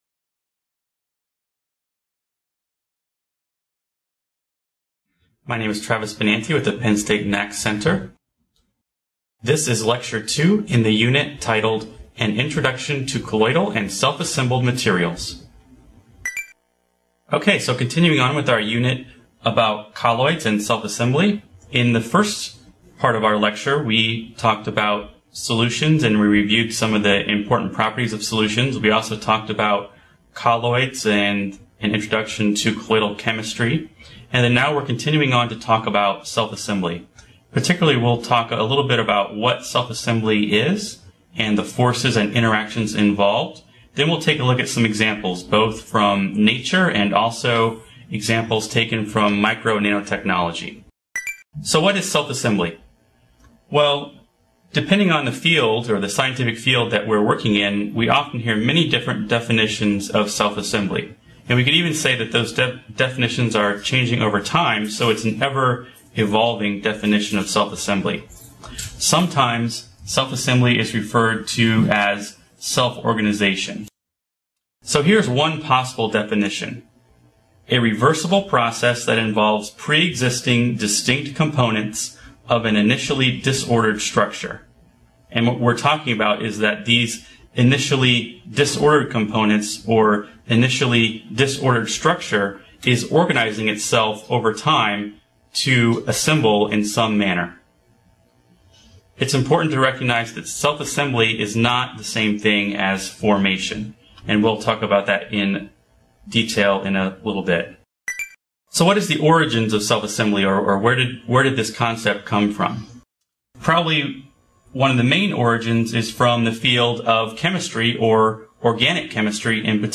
This video, published by the Nanotechnology Applications and Career Knowledge Support (NACK) Center at Pennsylvania State University, is part two of a two-part lecture on colloidal and self-assembled materials.